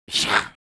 Index of /App/sound/monster/orc_magician
attack_1.wav